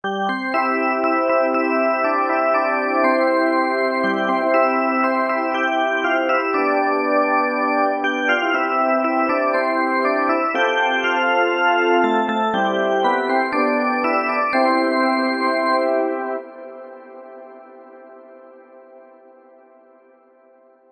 Because of this, even if it is digital synthesis, it sounds "warm".
All these demos are recorded directly from the ZynAddSubFX without audio processing with another program (well, exeption cutting/ OGG Vorbis compressing).